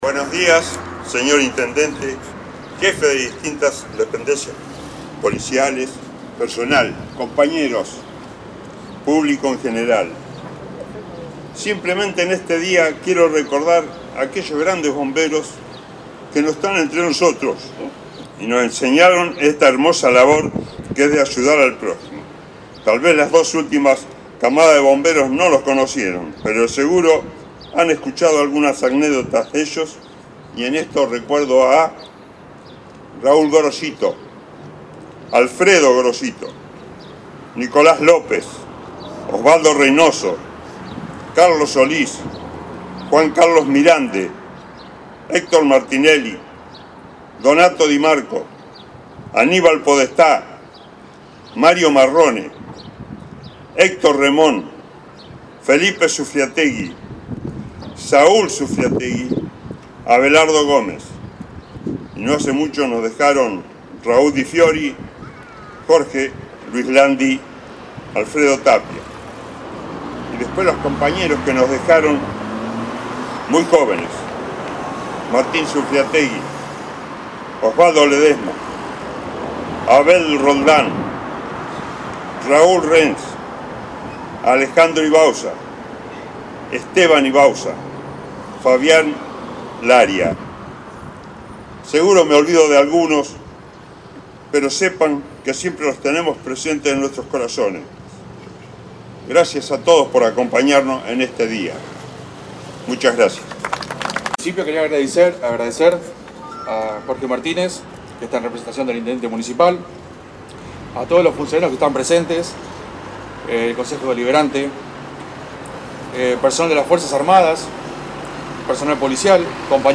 Este viernes 6 de septiembre se cumple el 137º aniversario de la creación del Cuerpo de Bomberos de la Policía de la Provincia de Buenos Aires, cuyo acto central en Necochea fue en el monumento de la fuerza en avenidas 42 y 59.